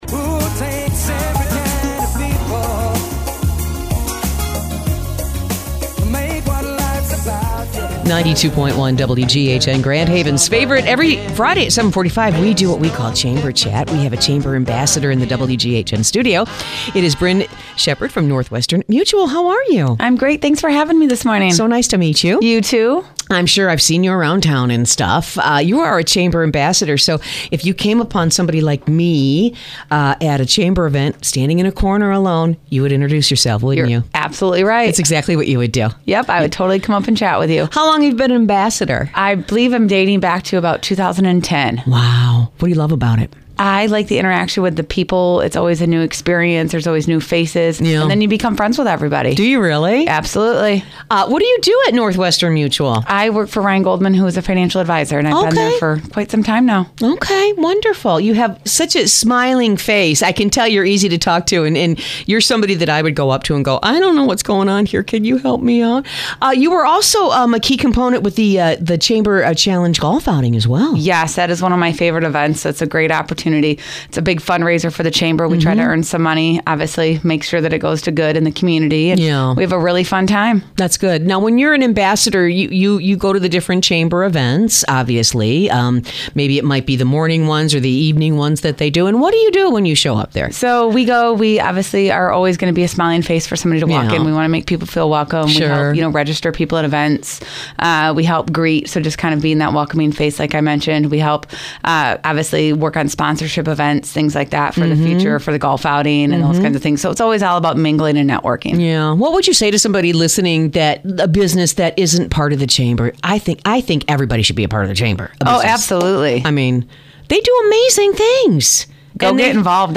she shares what she loves about it in this fun interview.